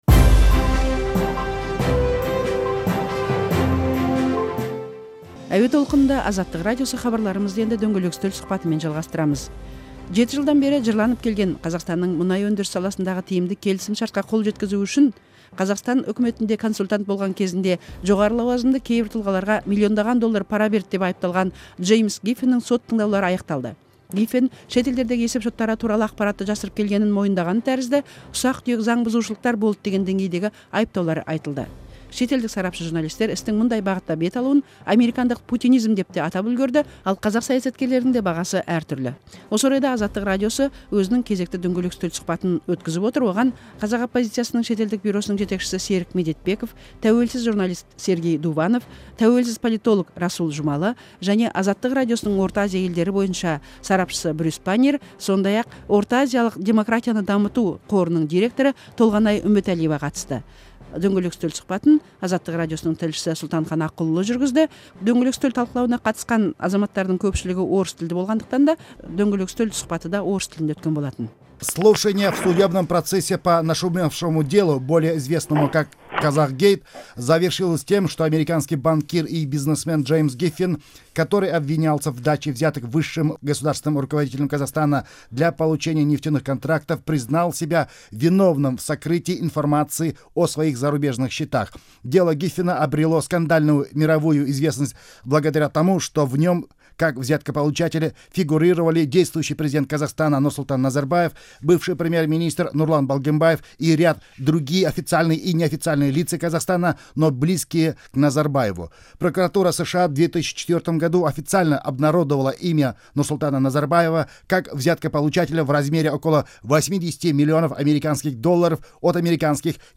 Қазақгейт тақырыбында өткен сұқбатты тыңдаңыз